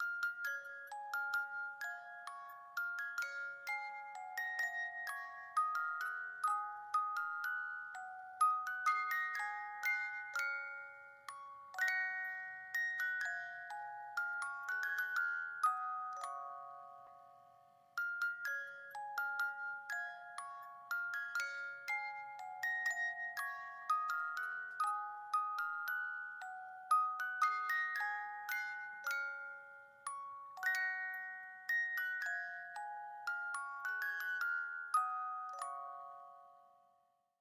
Музыкальная шкатулка наигрывает колыбельную мелодию